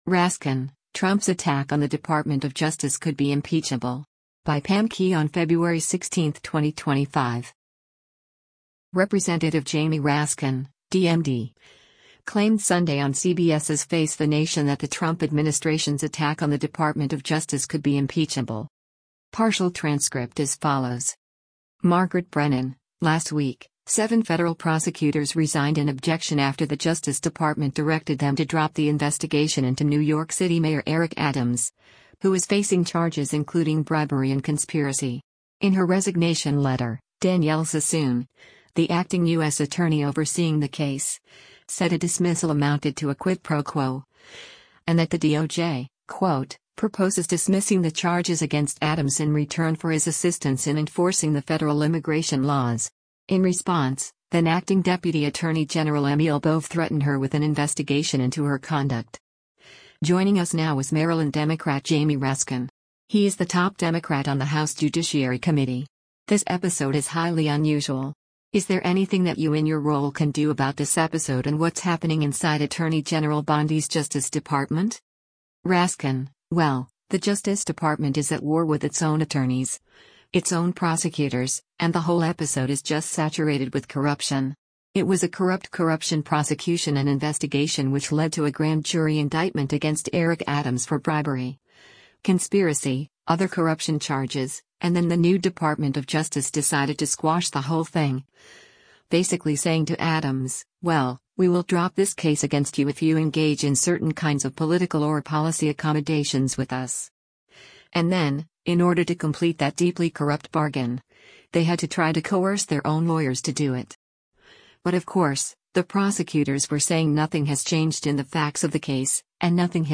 Representative Jamie Raskin (D-MD) claimed Sunday on CBS’s “Face the Nation” that the Trump administration’s “attack on the Department of Justice” could be “impeachable.”